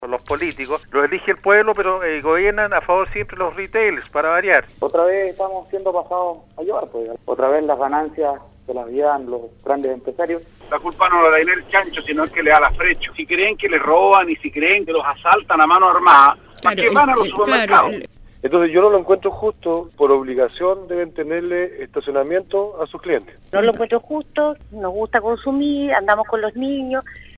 Una iniciativa que poco gustó al menos, en auditores que manifestaron su opinión en el programa Primera Hora de Radio Sago, donde estimaban que la ley favorece al retail antes que al consumidor.